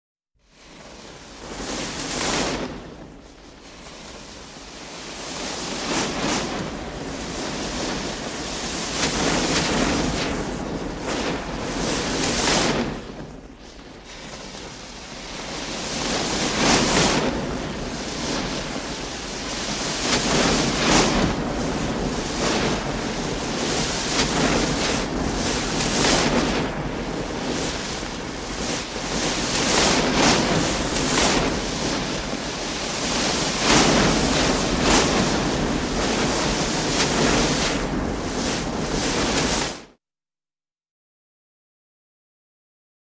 Fuse Burning Up To Dynamite Or Black Power Keg, Extended Length